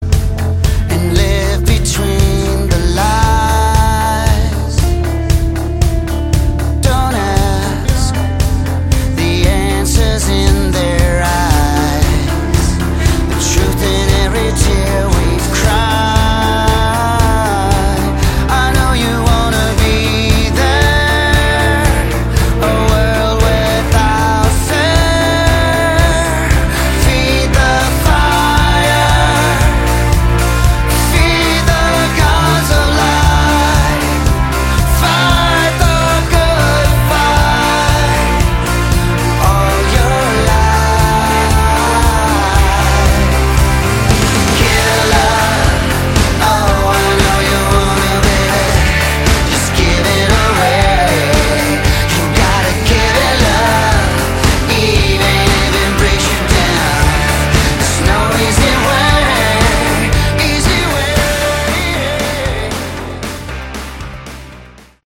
Category: Melodic Rock
Far too mellow.